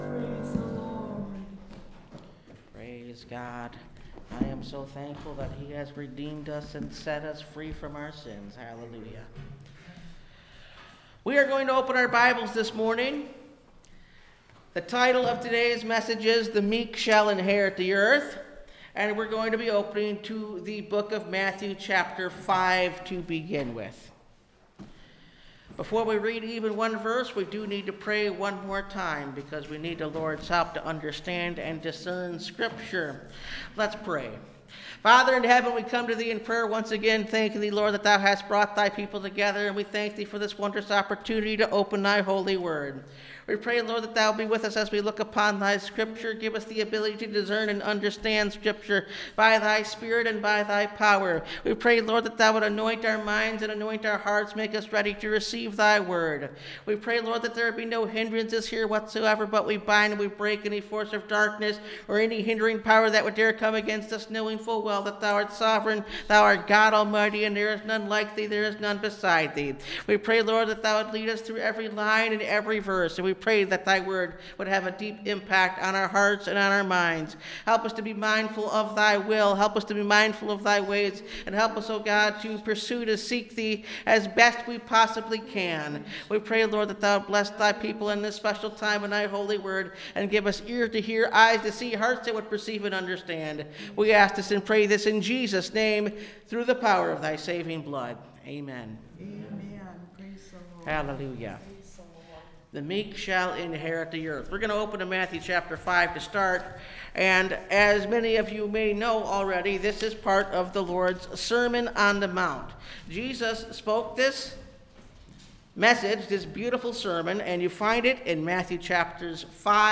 The Meek Shall Inherit The Earth (Message Audio) – Last Trumpet Ministries – Truth Tabernacle – Sermon Library
Service Type: Sunday Morning